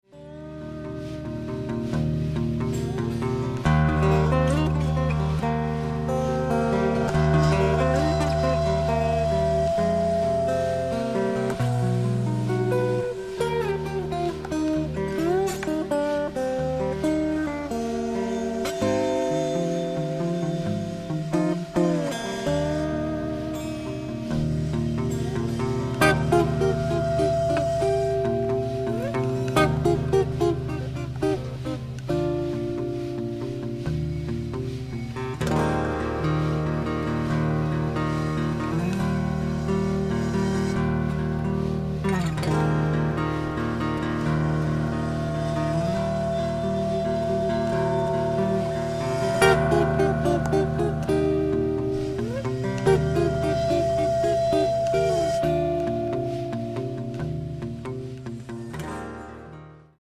guitars
percussions, items